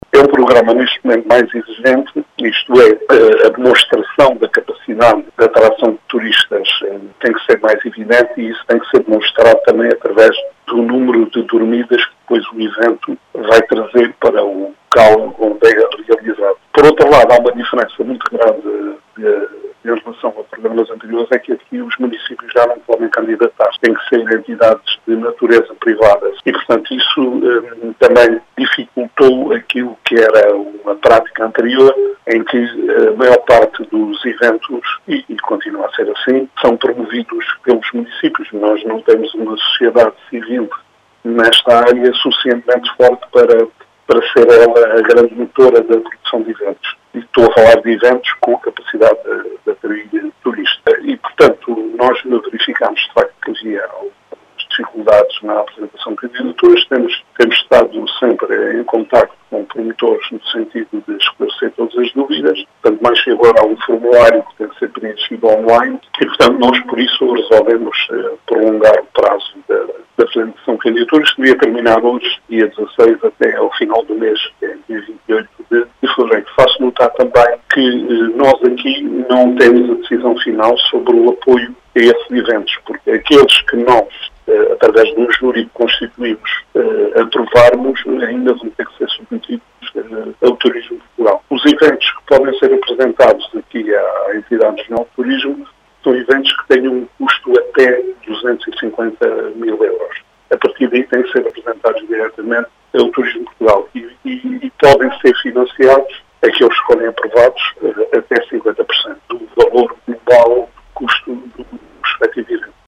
As explicações são de Vitor Silva, presidente da Entidade Regional de Turismo do Alentejo e Ribatejo.